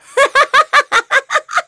Aselica-Vox_Happy3.wav